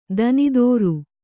♪ danidōru